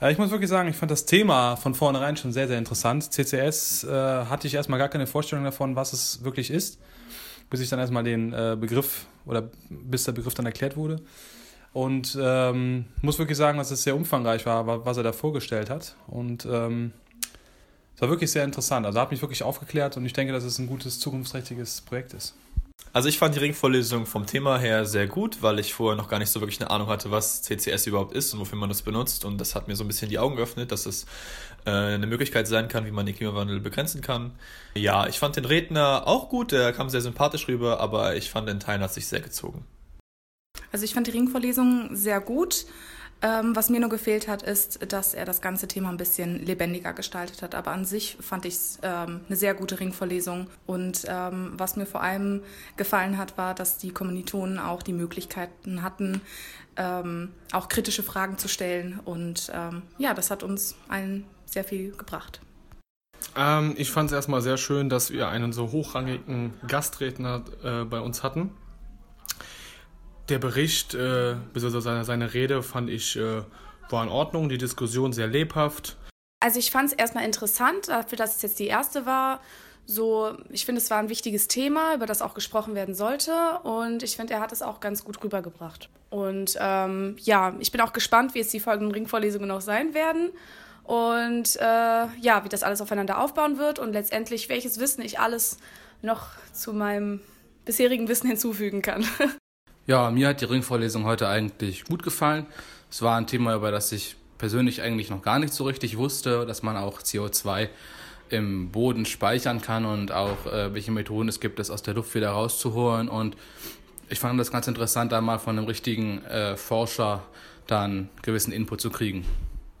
Hier einige Meinungen aus der Studierendenschaft: